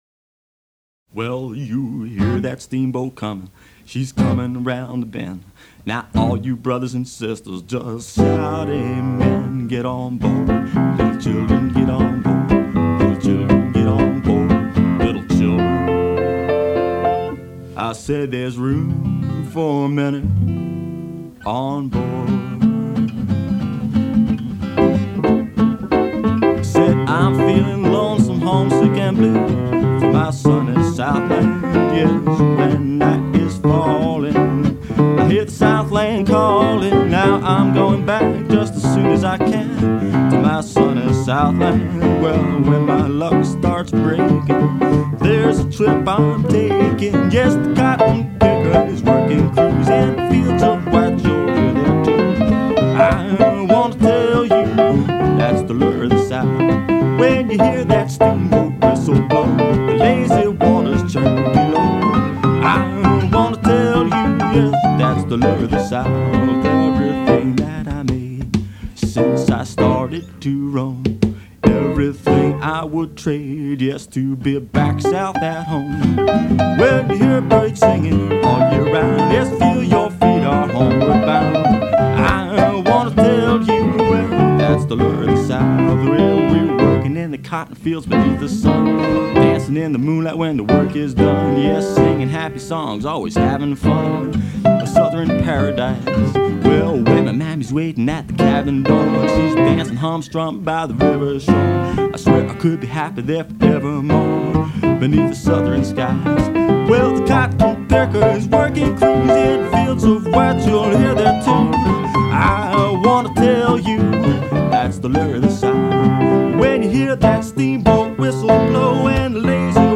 Recorded in the early 1980's in a chicken coop on the outskirts of town, these were some early interpretations that attempted to capture the spirit of Leroy Carr and Scrapper Blackwell, as well as the "churchy feel" music that is so predominant in the Memphis and Mississippi Delta area.